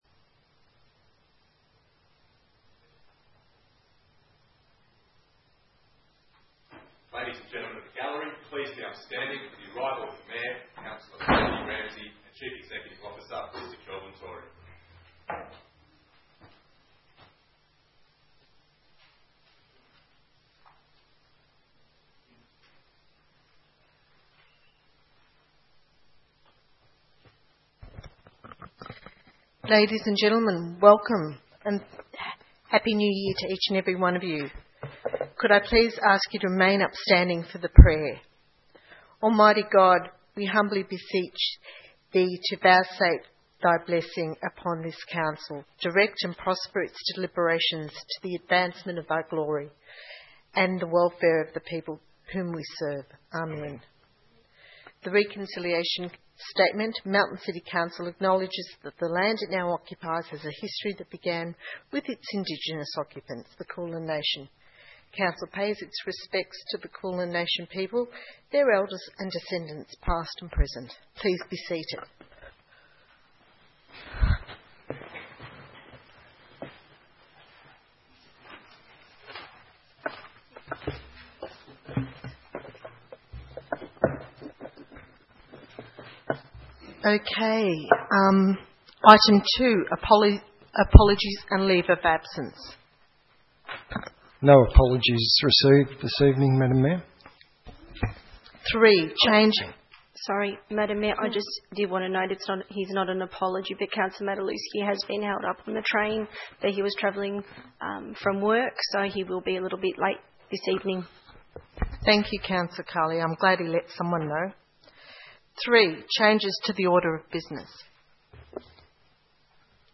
3 February 2015 - Ordinary Council Meeting